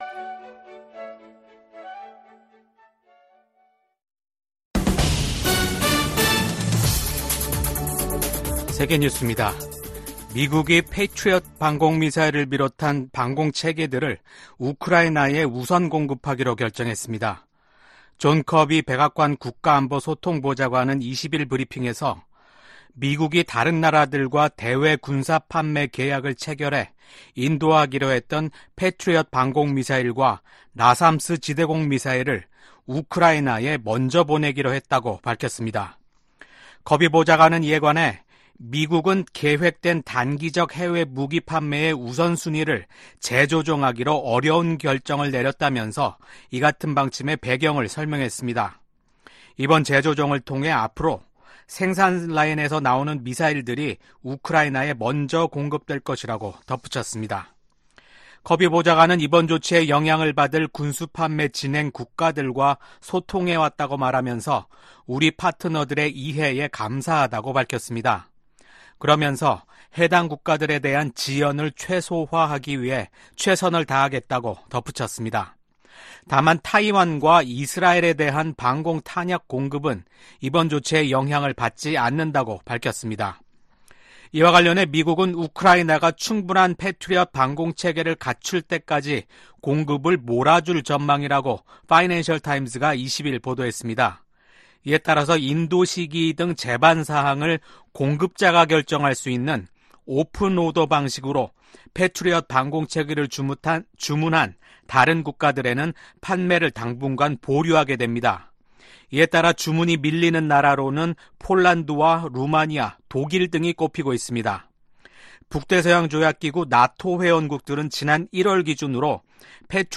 VOA 한국어 아침 뉴스 프로그램 '워싱턴 뉴스 광장' 2024년 6월 22일 방송입니다. 북한과 러시아가 군사협력 조약을 체결한 데 대해 미국 백악관과 국무부, 국방부등이 우려를 나타냈습니다.